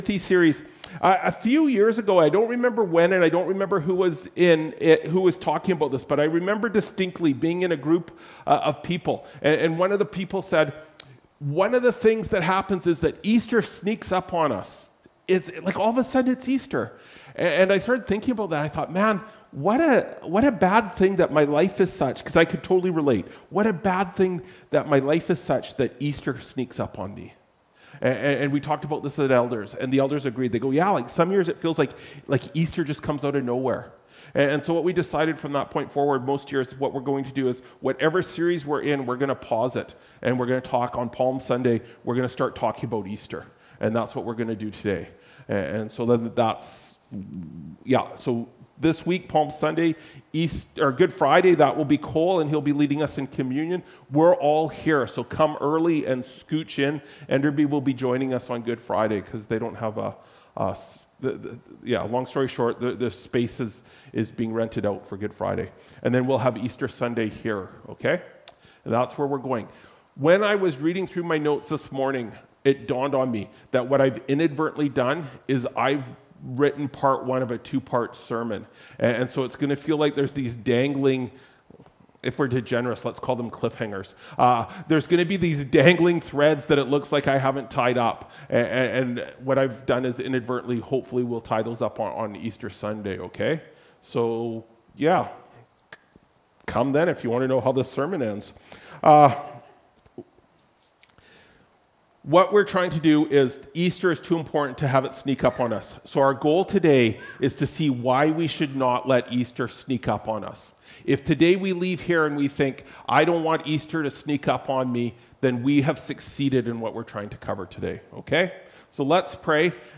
Apr 13, 2025 Don’t Waste Holy Week (John 12:12–19) MP3 SUBSCRIBE on iTunes(Podcast) Notes Discussion Sermons in this Series This sermon was recorded in Salmon Arm and preached in both campuses.